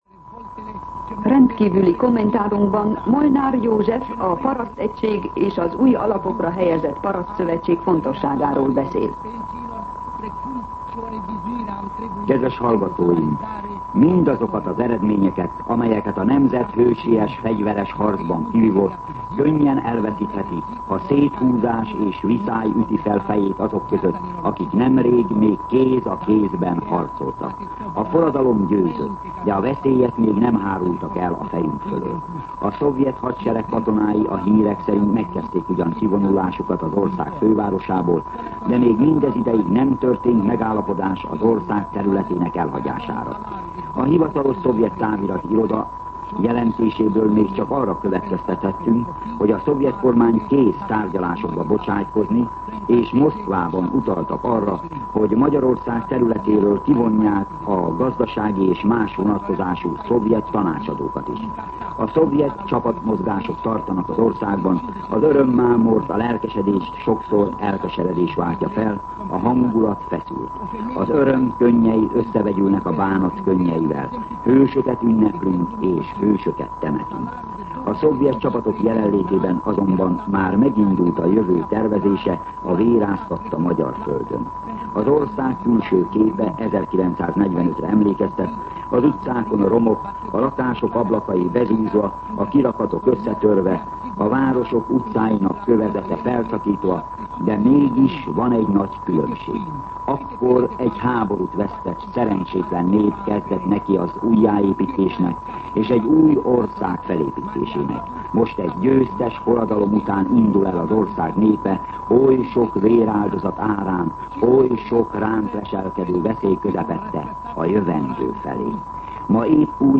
Rendkívüli kommentár